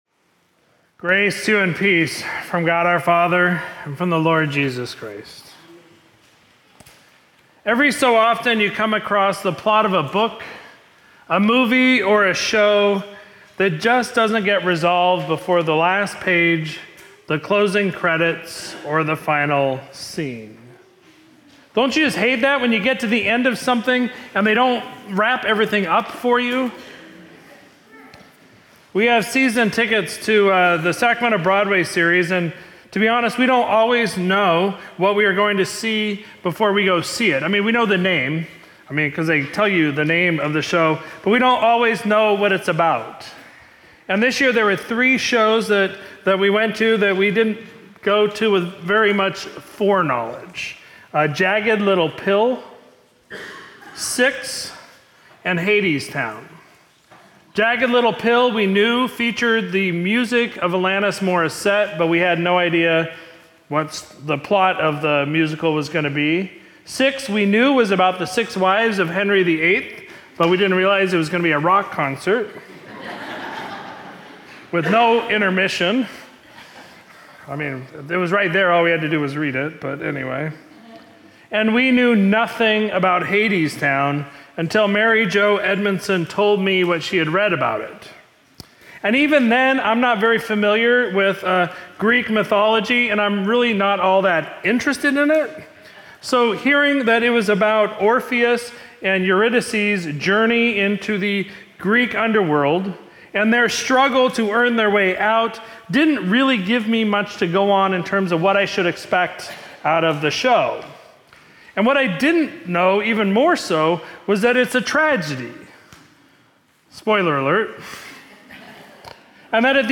Sermon from Sunday, March 31, 2024